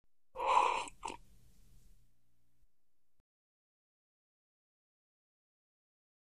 Sip Hot Liquid With Air In During Sip